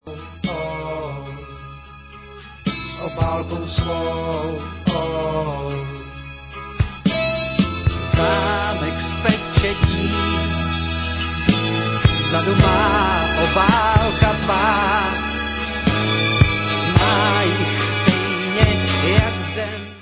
sledovat novinky v kategorii Rock